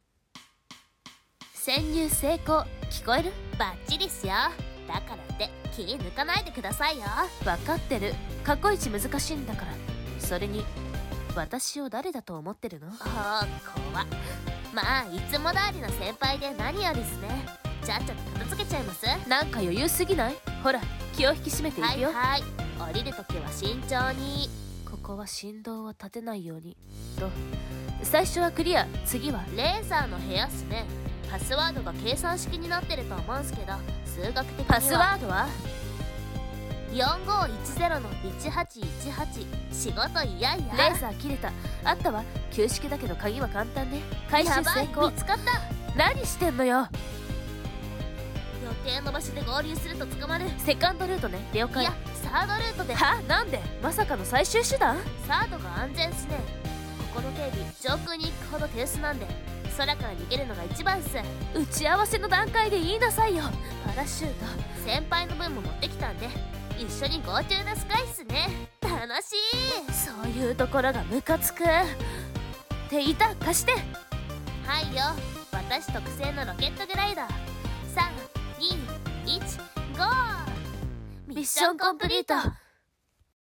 【声劇】蒼い空への逃飛行 / 女泥棒 : 〇〇 / 助手 : 〇〇